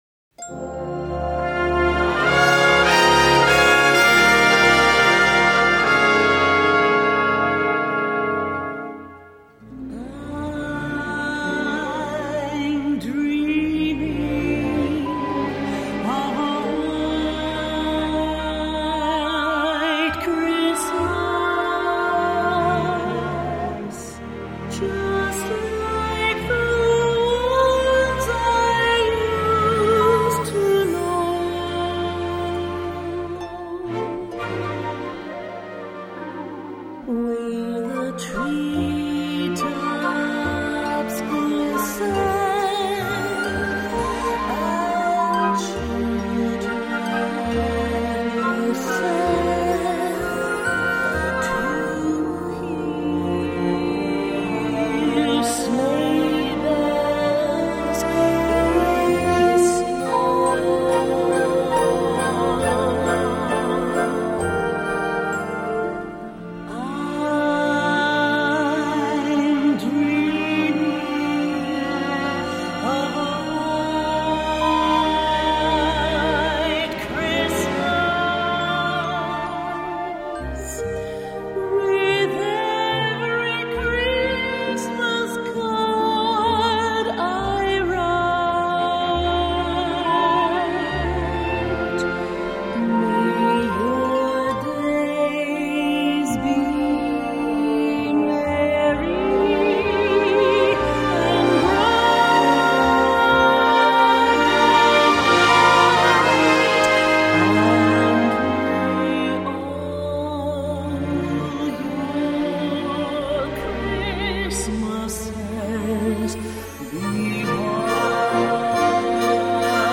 Voicing: Orch/Voc